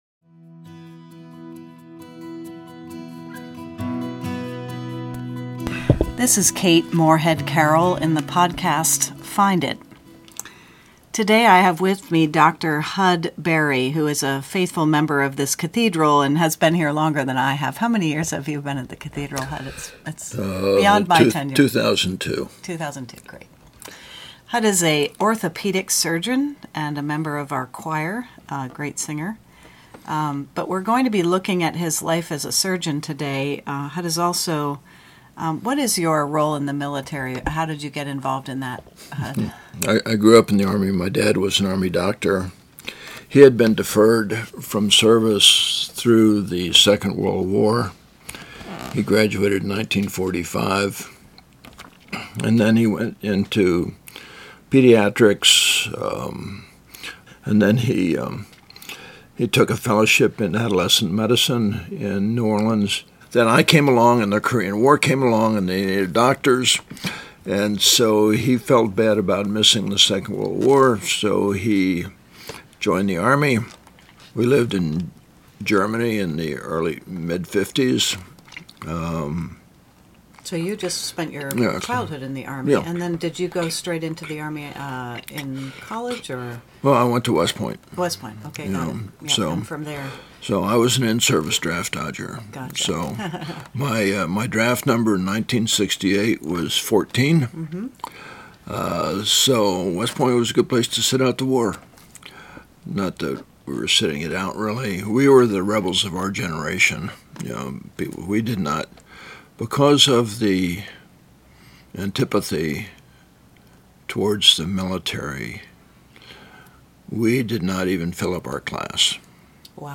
On the Ground in Ukraine: A Conversation